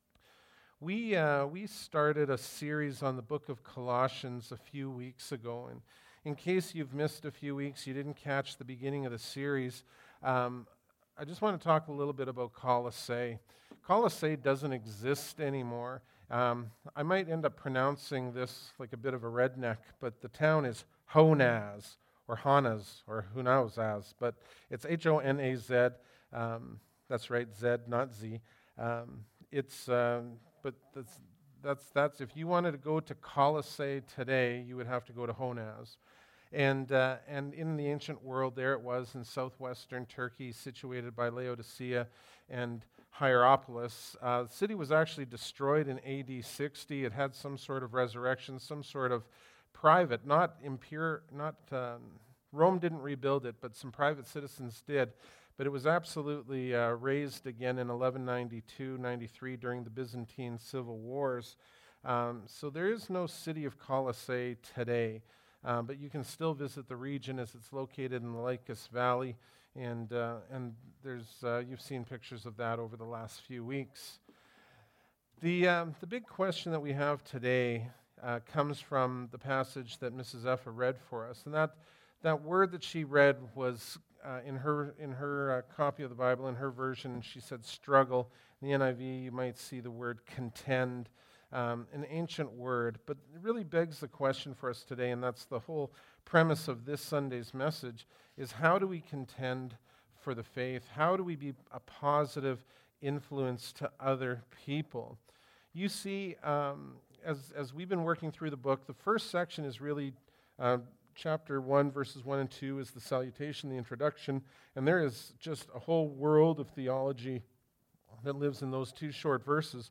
Bible Text: Colossians 2:1-5 | Preacher